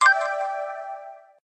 Custom new mail notification sounds
alert.ogg